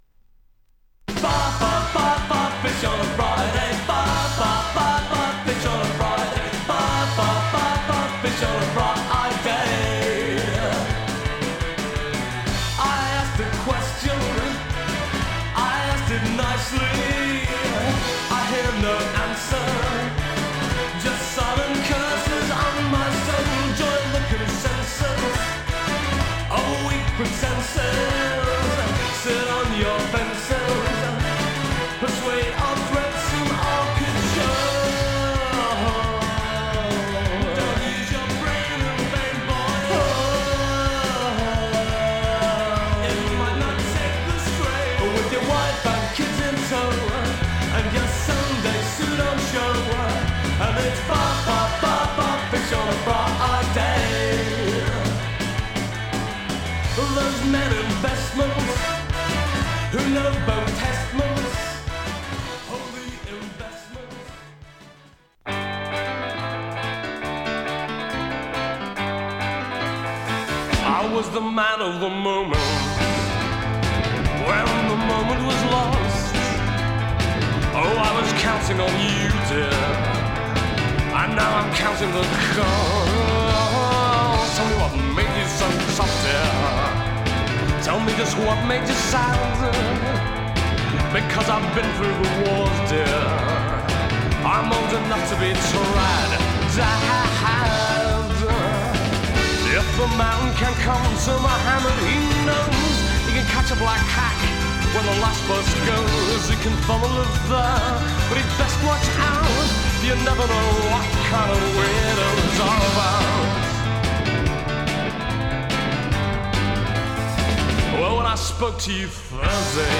ワルツの小粋なナンバー
ルルル〜♪コーラスがキャッチな
弾んだピアノが楽しい